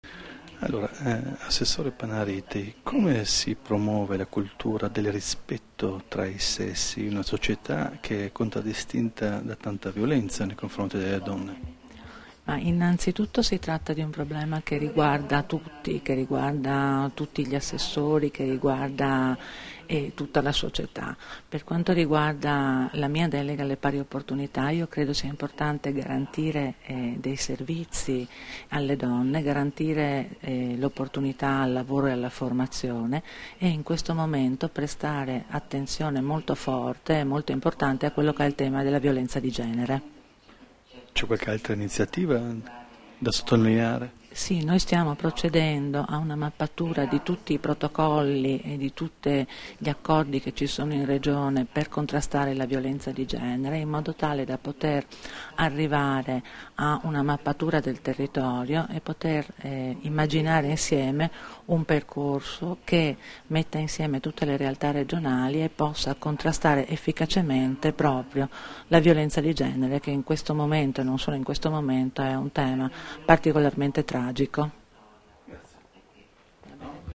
Dichiarazioni di Loredana Panariti (Formato MP3)
rilasciate a margine dell'incontro "Rispetto tra i sessi e pari opportunità tra donna e uomo", all'Auditorium della Regione Friuli Venezia Giulia a Udine il 25 settembre 2014